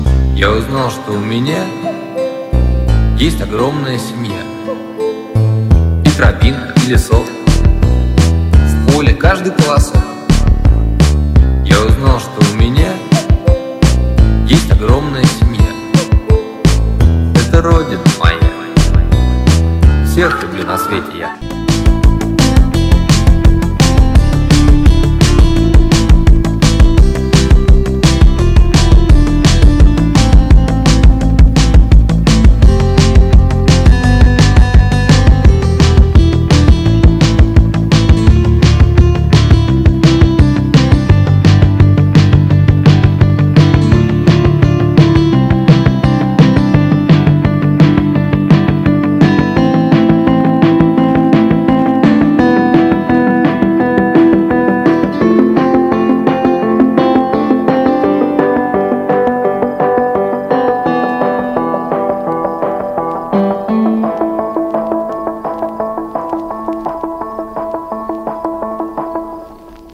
• Качество: 320, Stereo
гитара